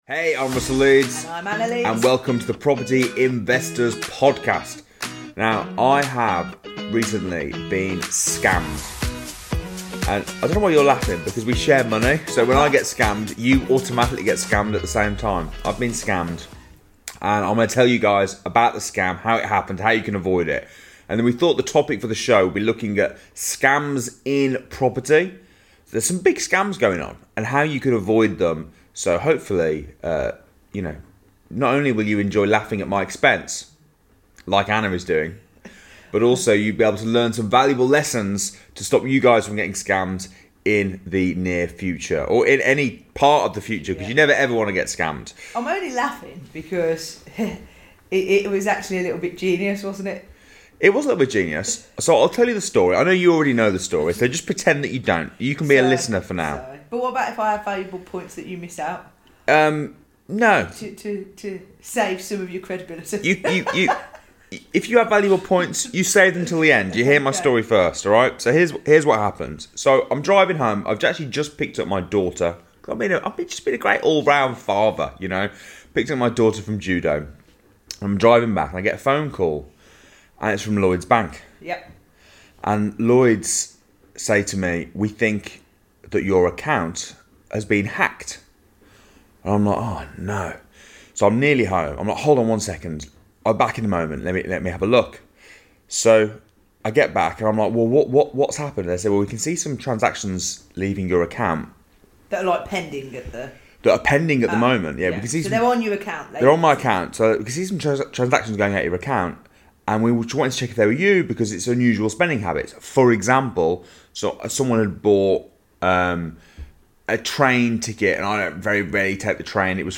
Welcome to the Property Investors Podcast, a light-hearted and opinionated podcast for aspiring and experienced property investors alike.